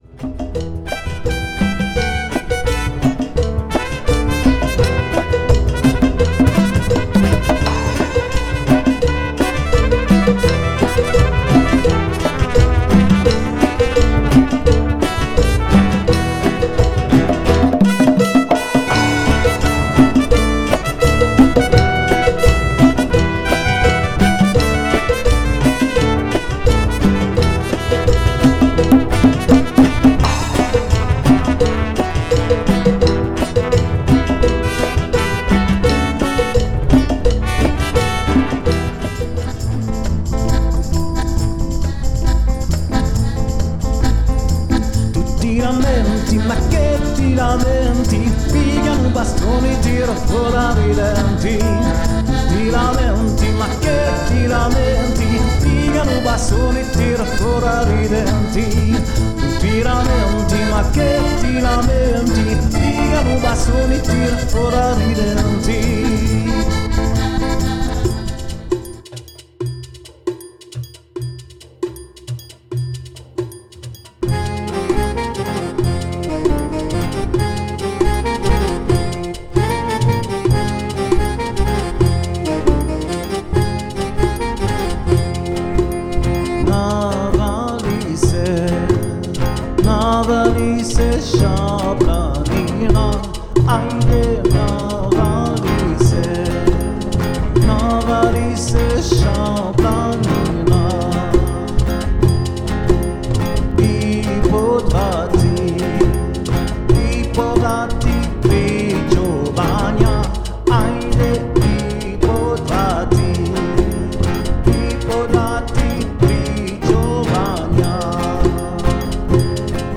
percussionniste / chanteur
contrebassiste
guitariste
Jazz d'influence ethnique Musiques du monde